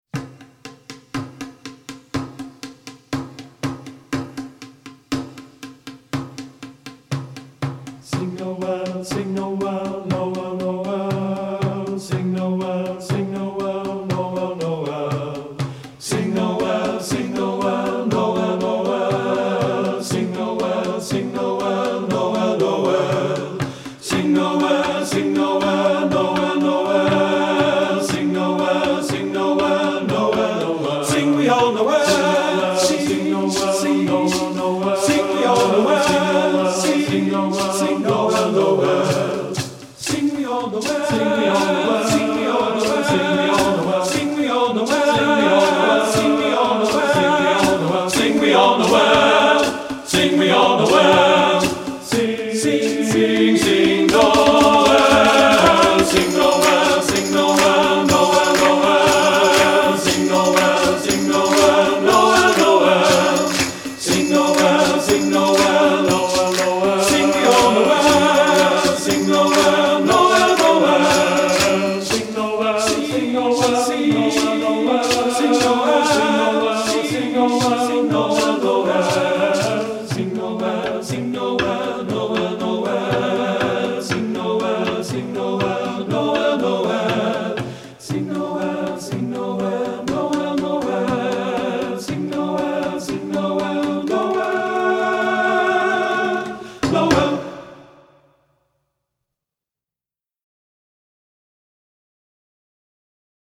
Voicing: TB Collection